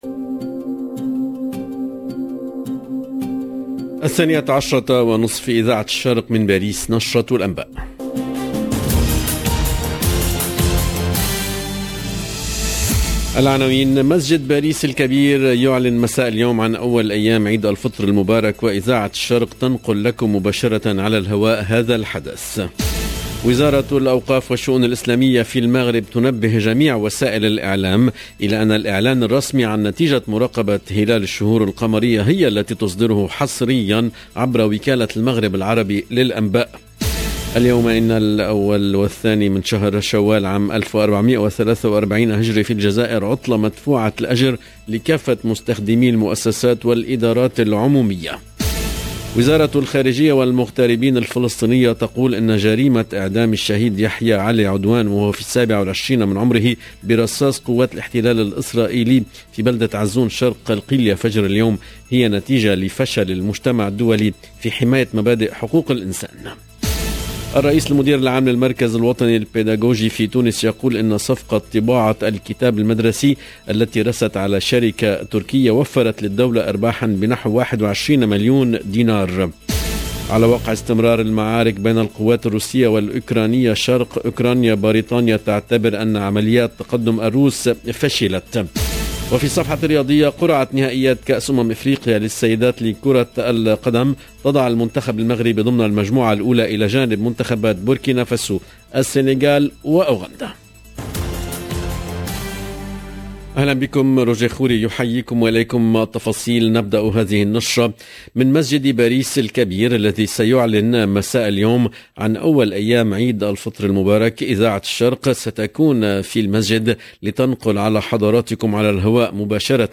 LE JOURNAL EN LANGUE ARABE DE MIDI 30 DU 30/04/22